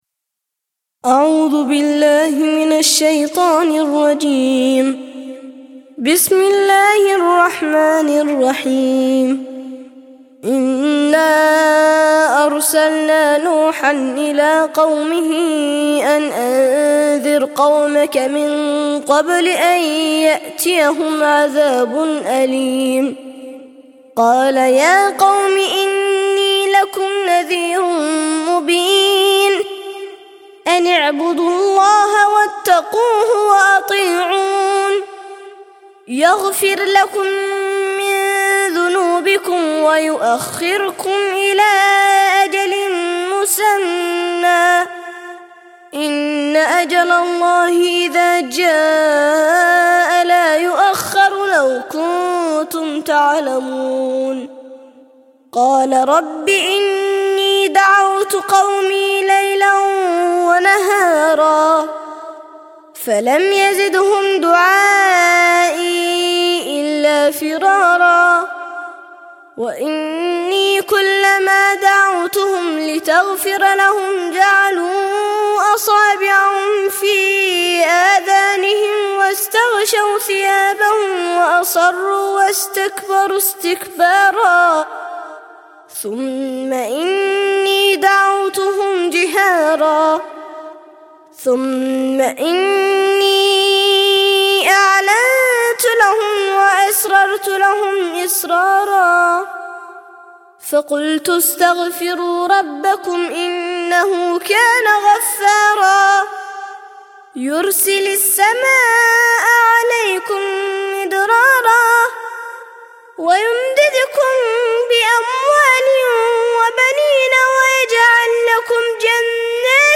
71- سورة نوح - ترتيل سورة نوح للأطفال لحفظ الملف في مجلد خاص اضغط بالزر الأيمن هنا ثم اختر (حفظ الهدف باسم - Save Target As) واختر المكان المناسب